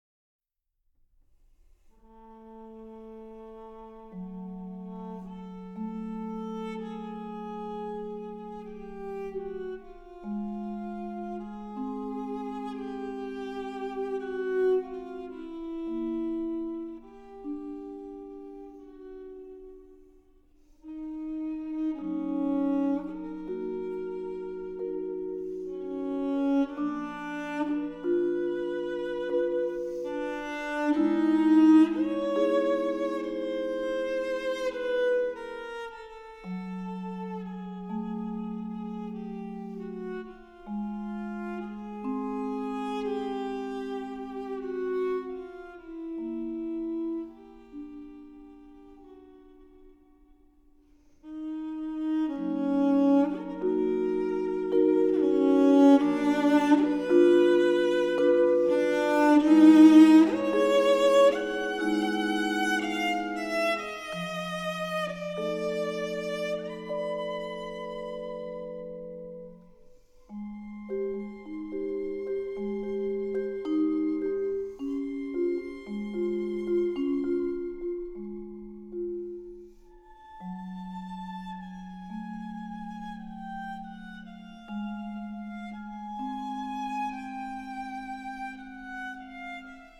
Violoncello
Vibraphon
Cello meets Vibraphone – Classic meets Jazz